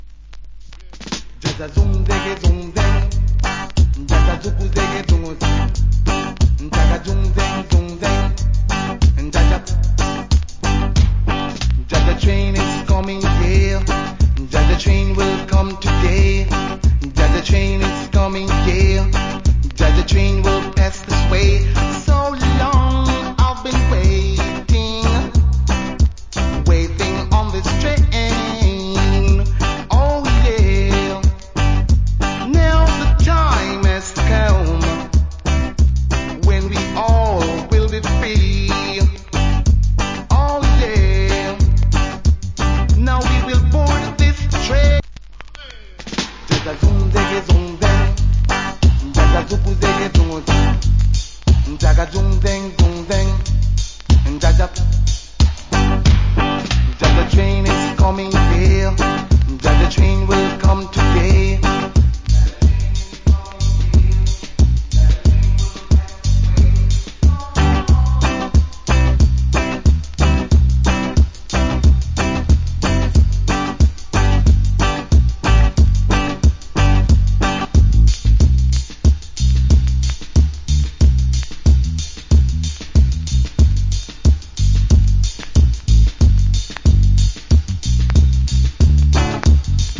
Rasta Cover